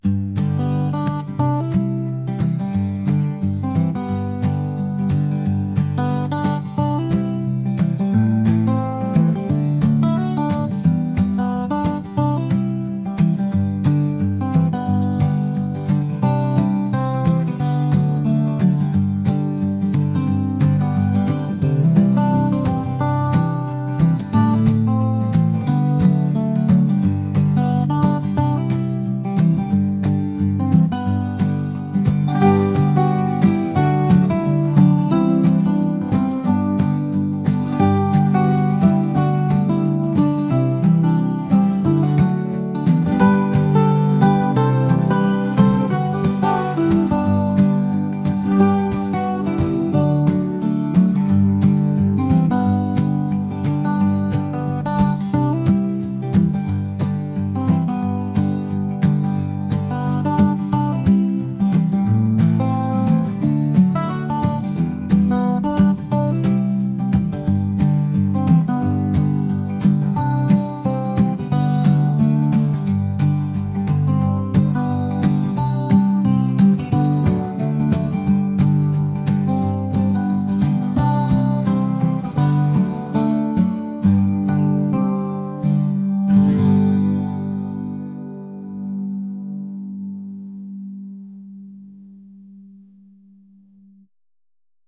Corporate
Folk